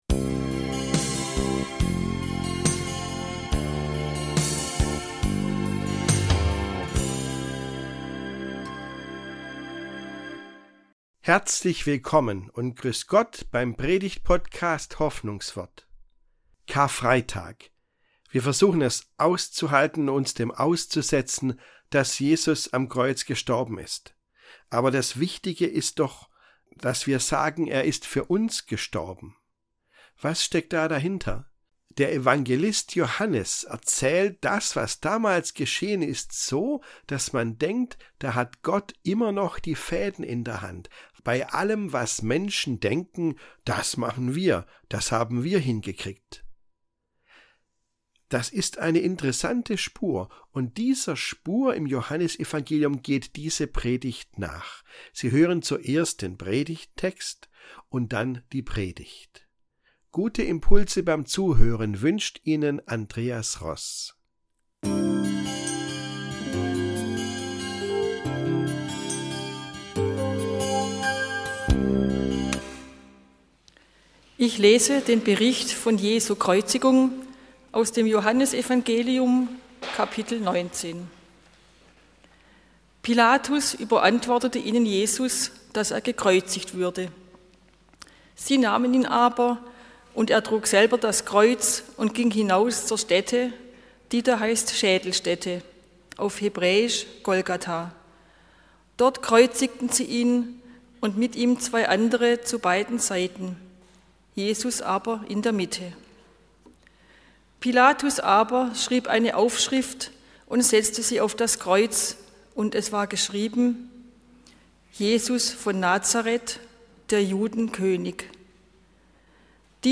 Die Predigt geht der Spur nach, die der Evangelist Johannes zeichnet.